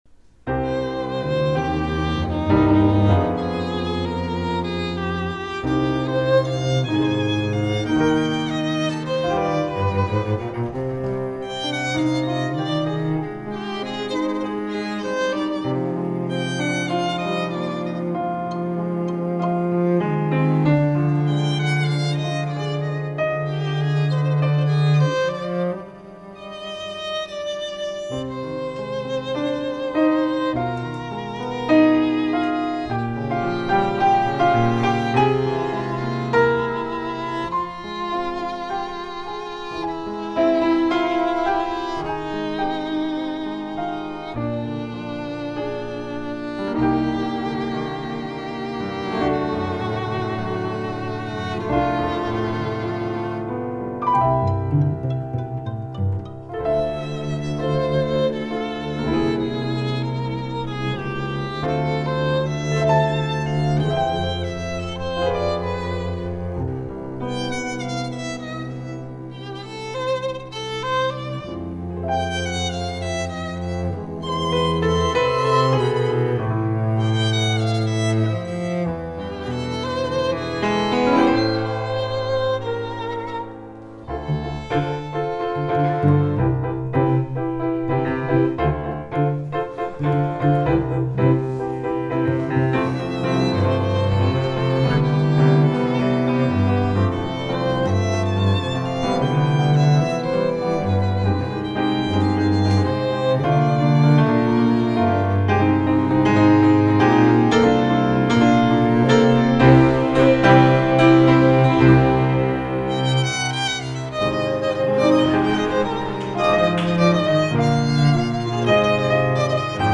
I present to you my relatively organic-sounding Mario mix:
A piano / cello / violin arrangement of several SMB1 themes.